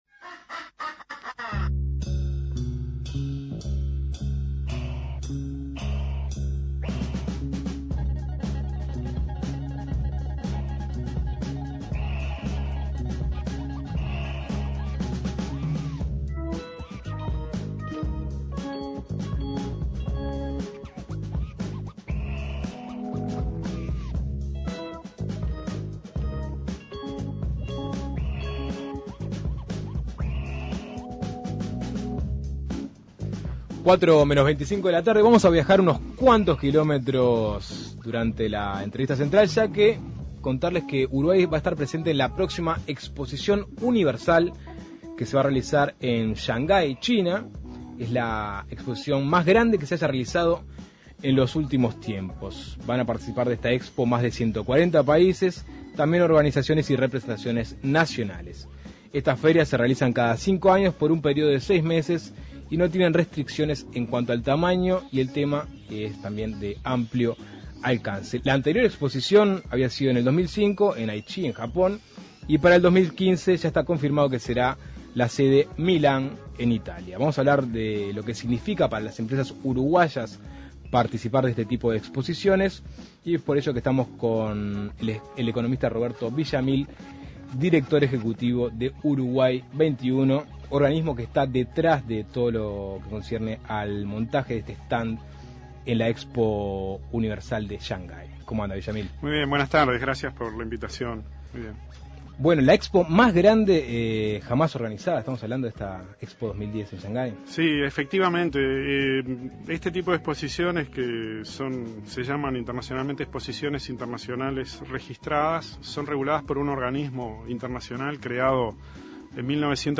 Este evento se realiza una vez cada cinco años y tienen una duración de seis meses. Escuche la entrevista.